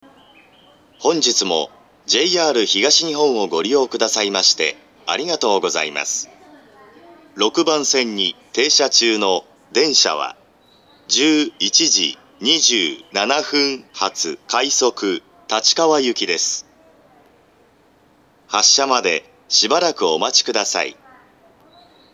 ２００９年１２月１８日には、自動放送が改良型ＡＴＯＳ放送に更新されました。
６番線出発予告放送
kawasaki6bansen-jihatu4.mp3